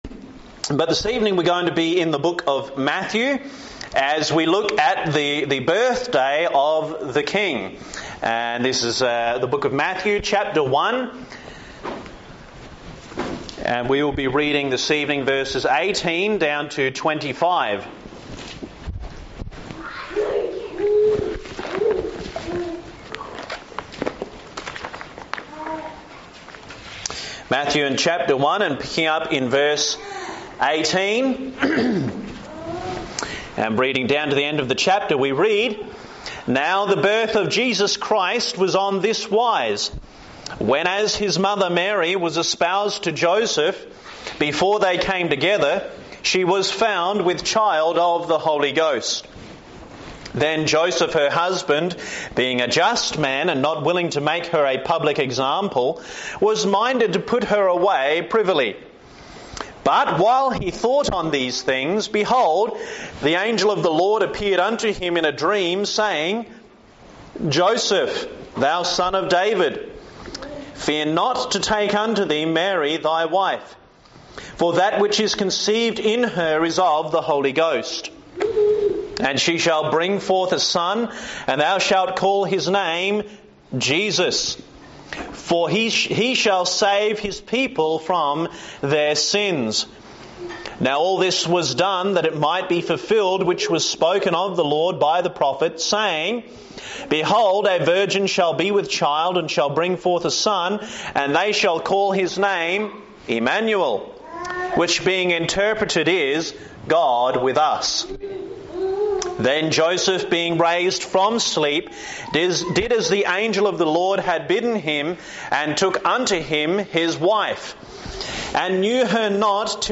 Intro: This evening, since this is Christmas week, I want to do a short study of Mathew’s account of the birth of the Lord Jesus. We will focus specifically on the immaculate birth of the Lord Jesus.